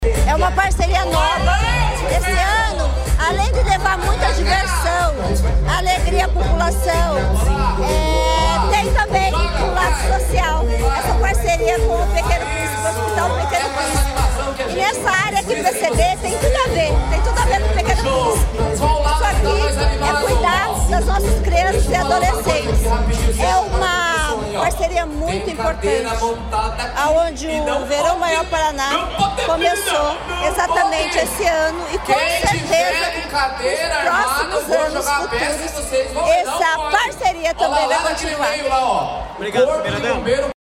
Sonora da primeira-dama do Estado